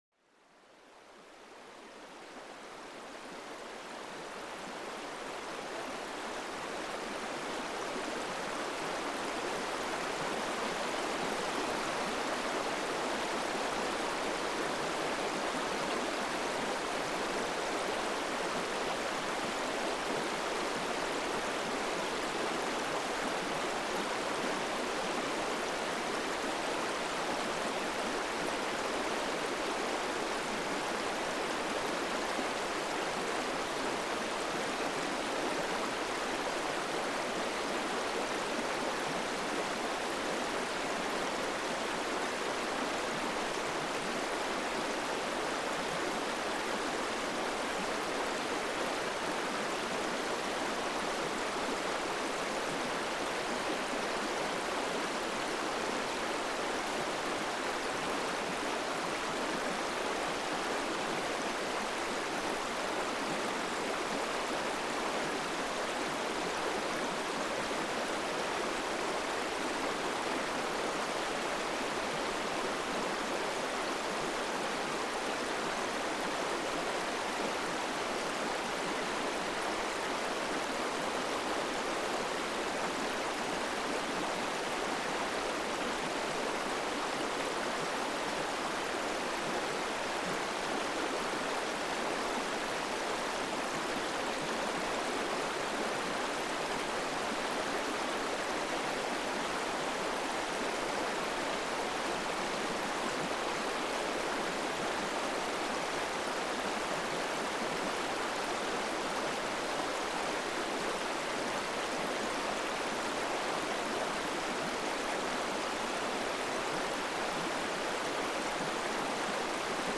Le doux murmure du cours d’eau pour apaiser votre esprit